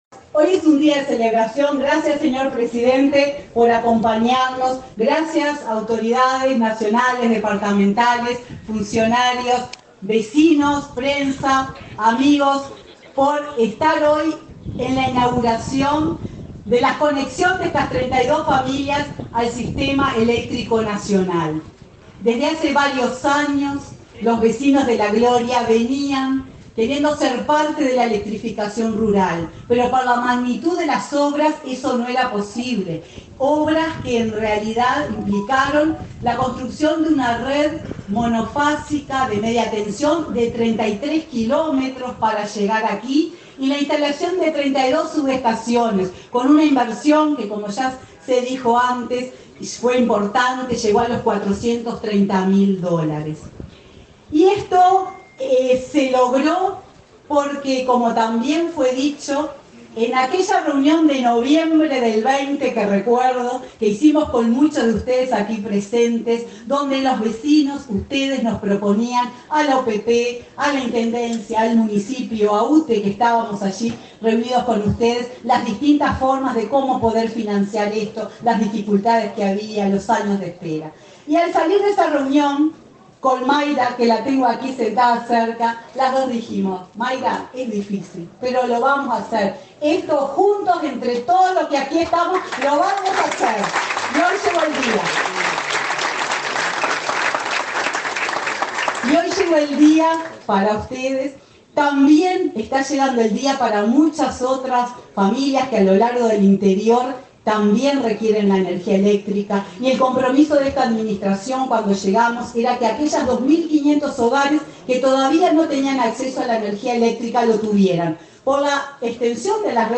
Palabra de autoridades en acto de UTE en Cerro Largo
Este viernes 1.°, el presidenta de UTE, Silvia Emaldi, y el intendente de Cerro largo, José Yurramendi, participaron del acto de inauguración de obras